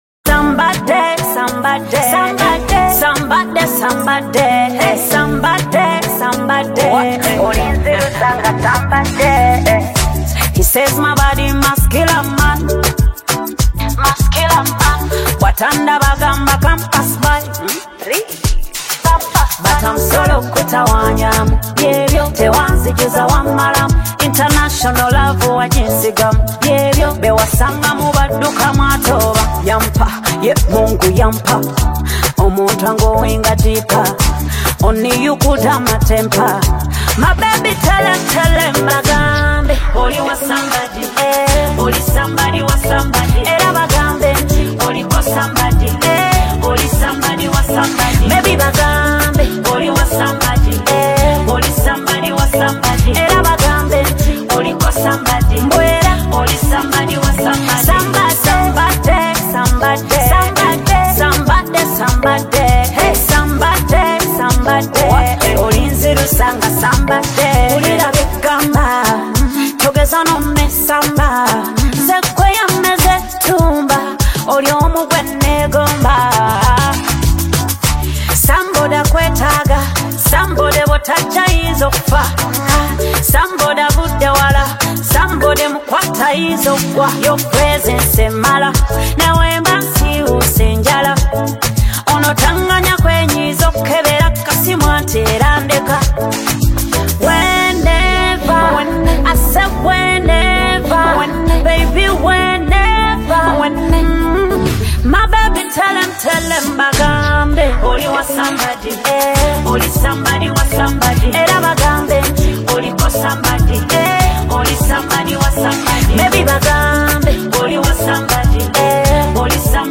isn’t just a danceable track